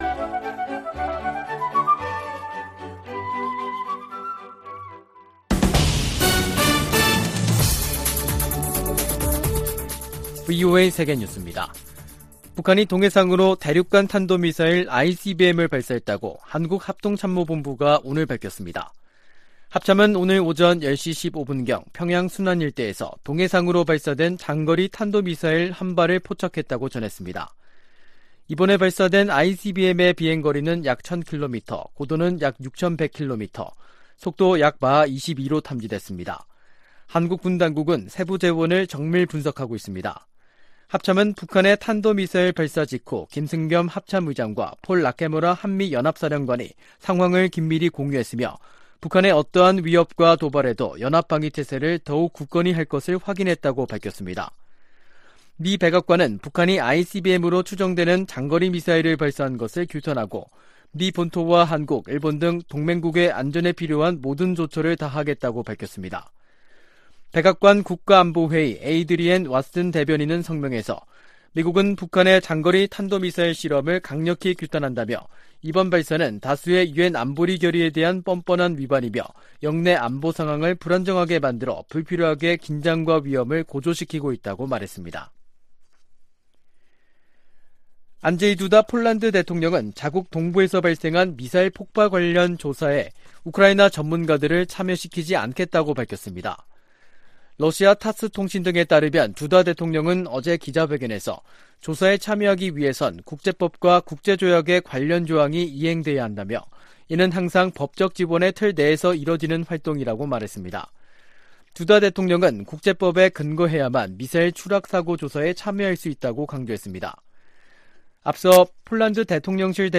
VOA 한국어 간판 뉴스 프로그램 '뉴스 투데이', 2022년 11월 18일 3부 방송입니다. 북한이 오늘, 18일, 신형 대륙간탄도미사일, ICBM인 ‘화성-17형’을 시험발사해 정상비행에 성공한 것으로 알려졌습니다. 한국 합동참모본부는 이에 대응해 F-35A 스텔스 전투기를 동원해 북한 이동식발사대(TEL) 모의표적을 타격하는 훈련을 실시했습니다.